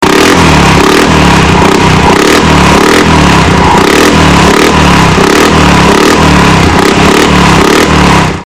buggy_exhaust.mp3